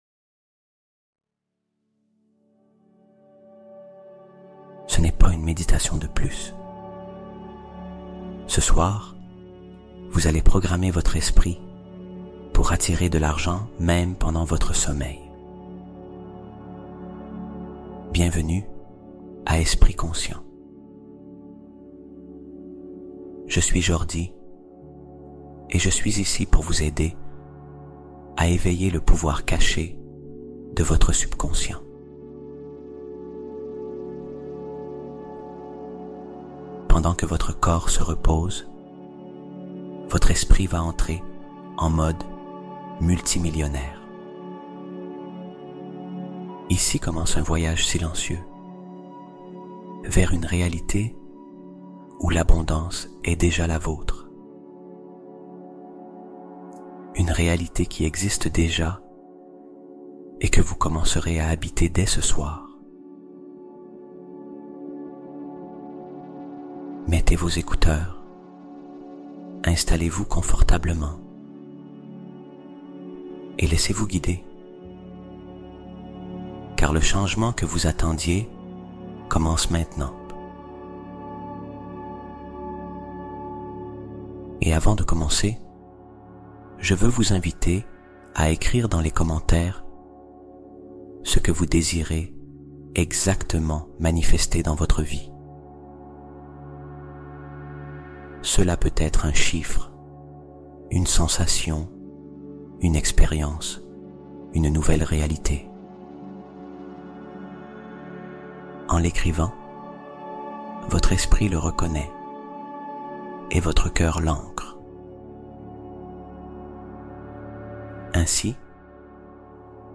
Deviens Millionnaire Pendant Ton Sommeil – Ondes Theta + Messages Subliminaux Interdits